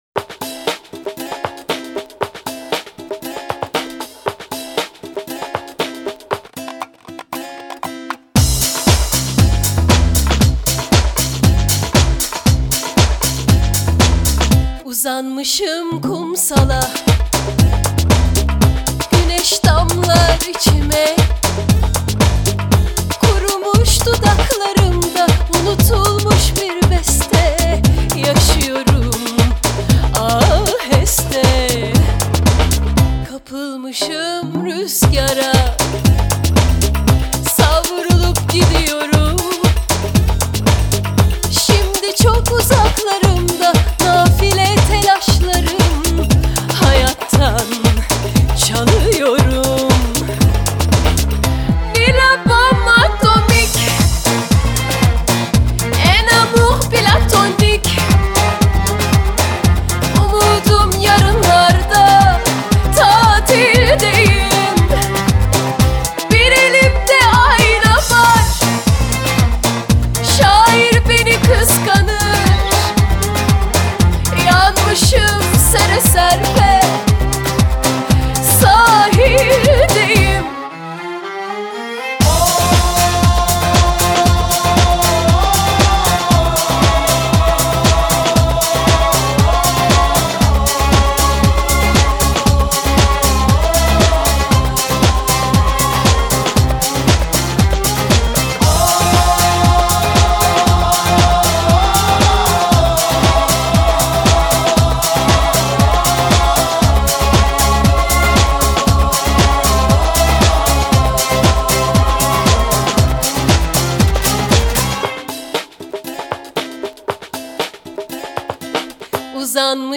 خواننده پاپ ترکی است.